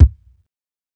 KICK_LIFE_AFTER_DEATH.wav